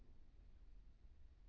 room_dishwasher-15-37.wav